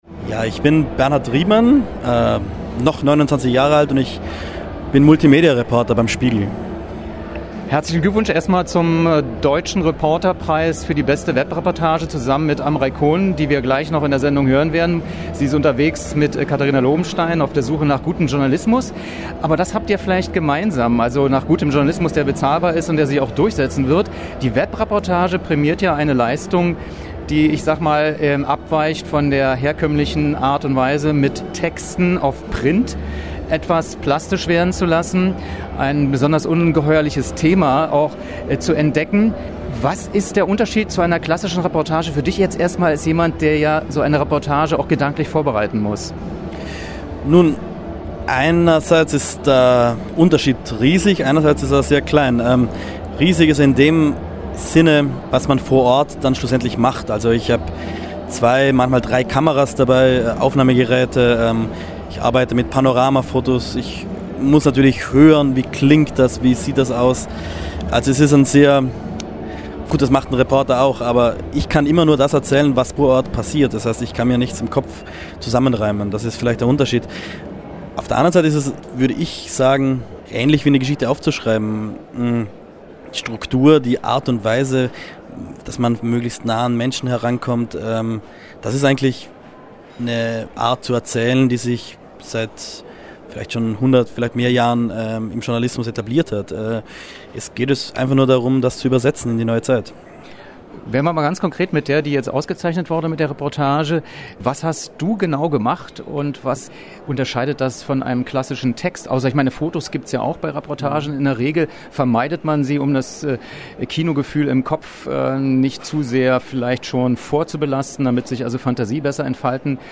Was: Interview zur Webreportage „Nicht von Gott gewollt“ Wann: 03.12.2012 Wo: Berlin, Universal Hall Vgl.: Mit dem Laden des Videos akzeptieren Sie die Datenschutzerklärung von Vimeo.